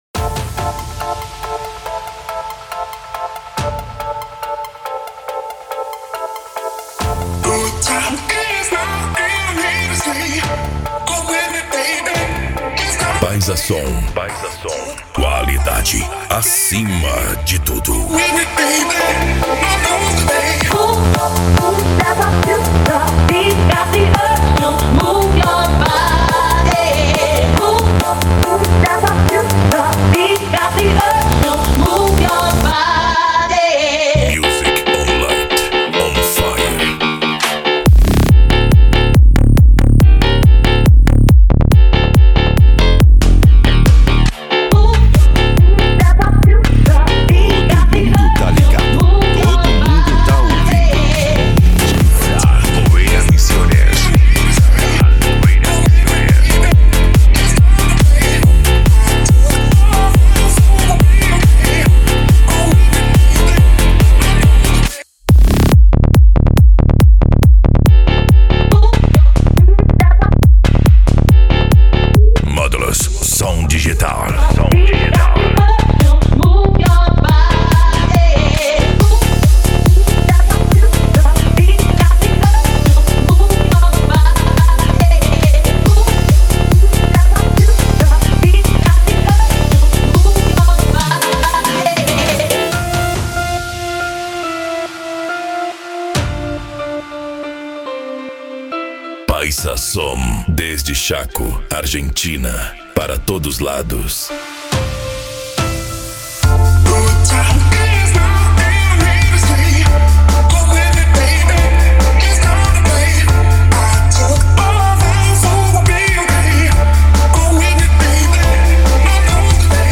Psy Trance
Racha De Som
Remix